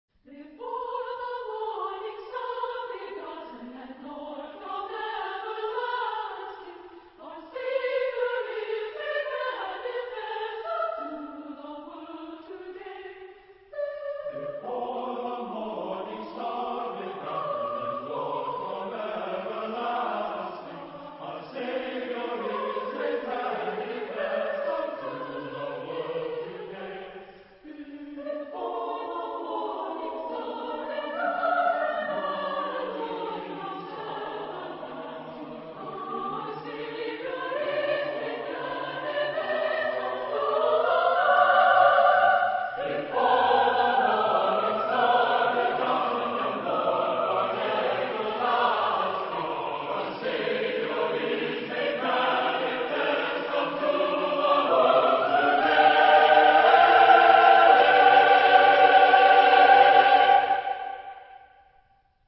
Genre-Stil-Form: Motette ; Weihnachtslied ; geistlich
Chorgattung: SATB  (4-stimmiger gemischter Chor )
Tonart(en): G-Dur